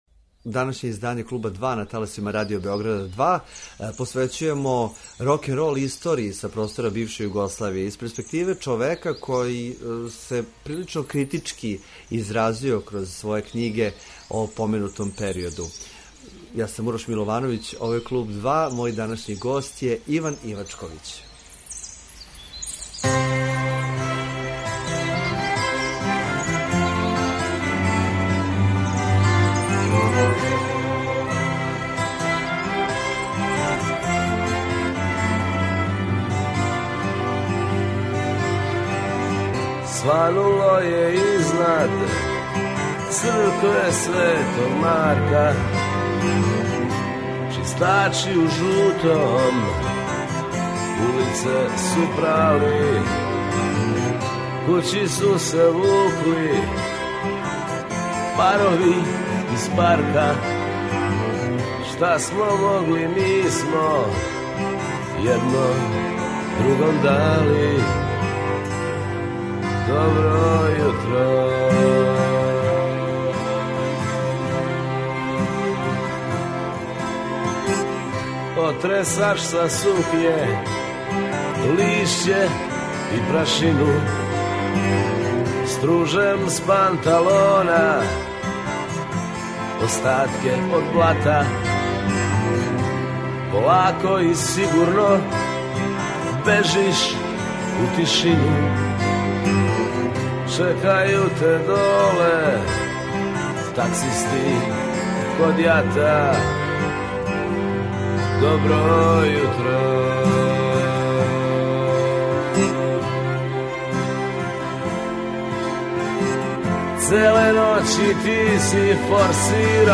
Током разговора изнео је шокантне констатације... Да ли је рокенрол као филозофија заиста пропао?